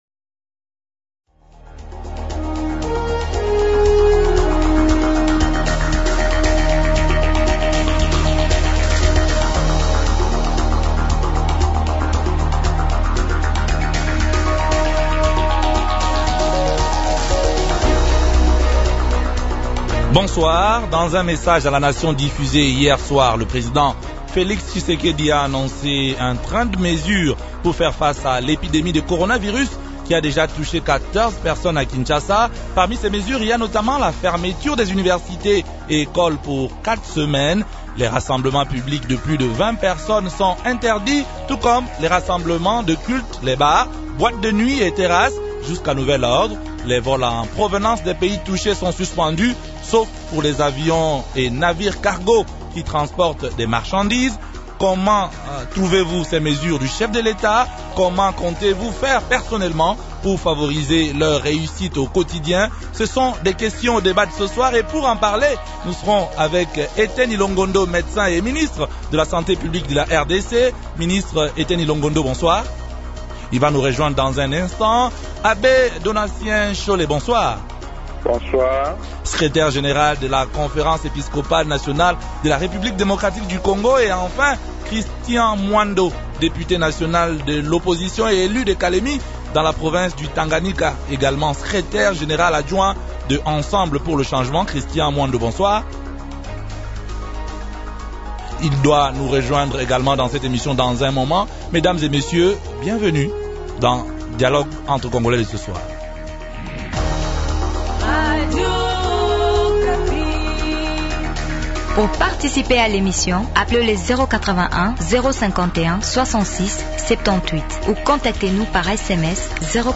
Christian Mwando, Député national de l’opposition, élu de Kalemie dans la province du Tanganyika.